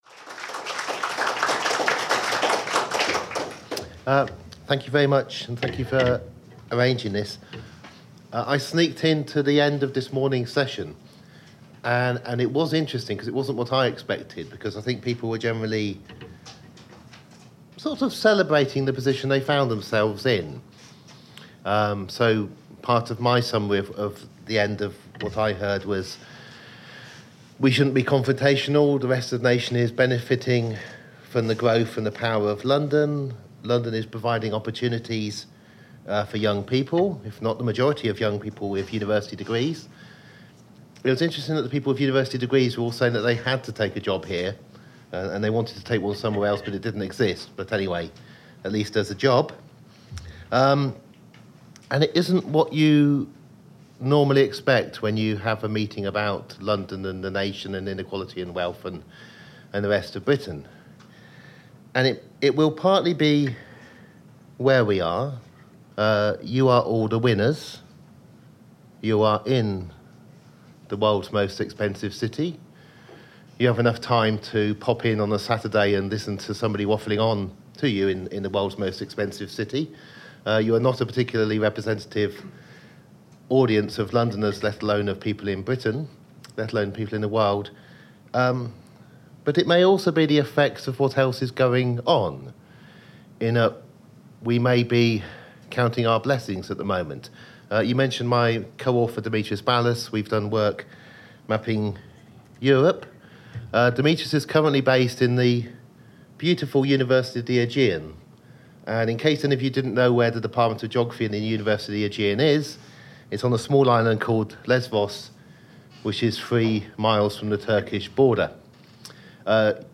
Danny Dorling talks as part of London and the Nation organised by the British Library and the Raphael Samuel History Centre.